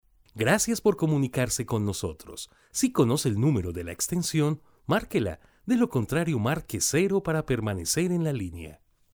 voz promocional, Cálida, cercana, emotiva, institucional
Locutor comercial , promocional, IVR , institucional, documental, E- learning, corporativa
spanisch Südamerika
Sprechprobe: Industrie (Muttersprache):